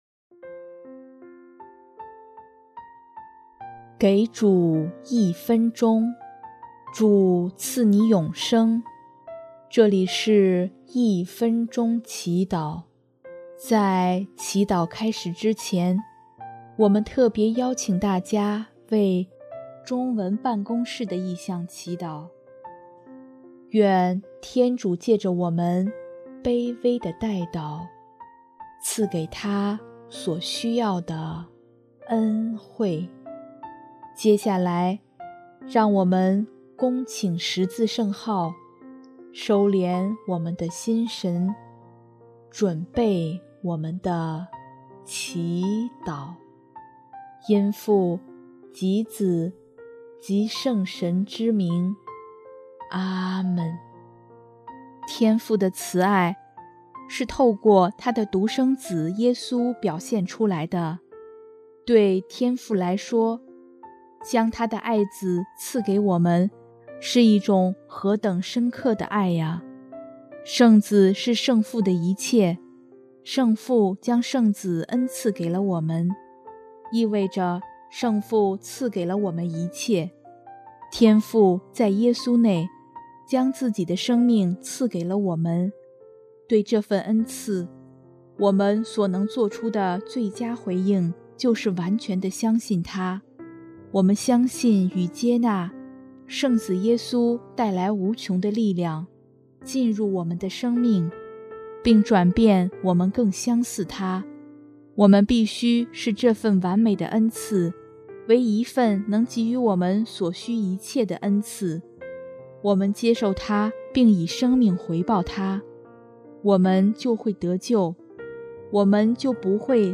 音乐：第四届华语圣歌大赛参赛歌曲《木匠》（中文办公室：求圣神带领中文部，在媒体上传播福音，使更多的人受益）